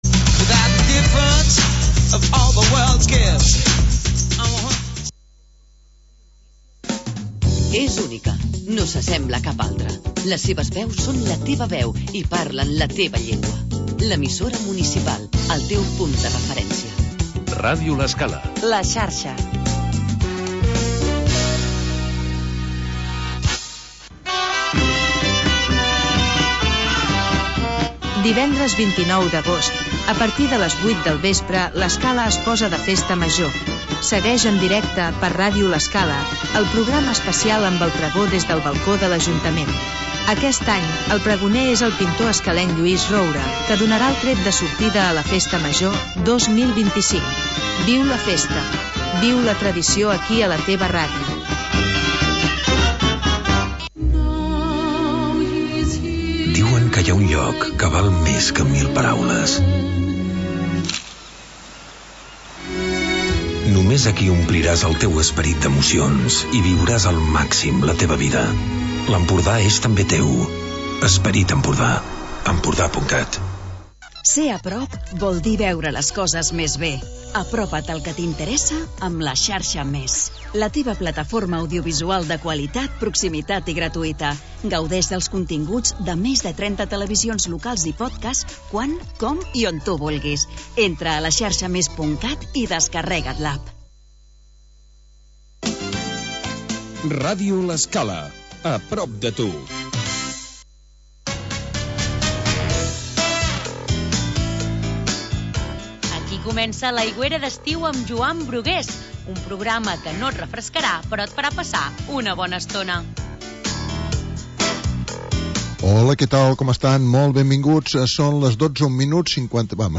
Magazín musical